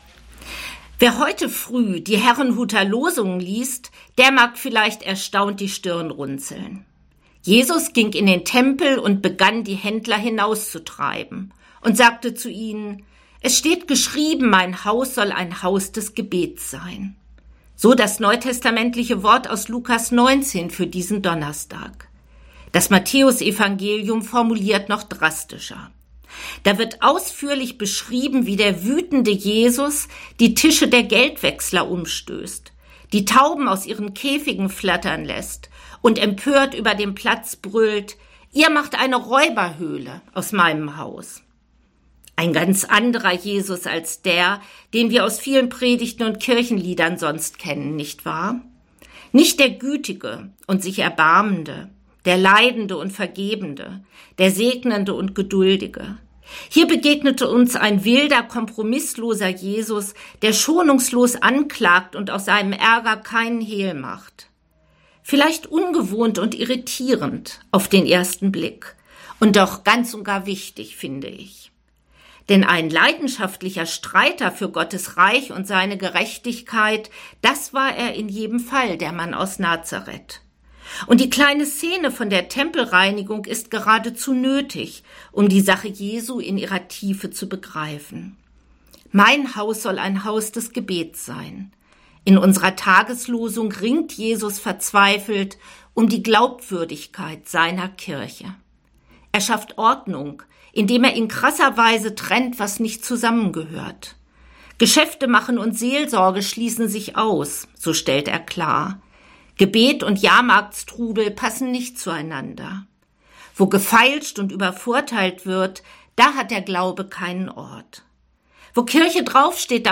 Radioandacht vom 21. September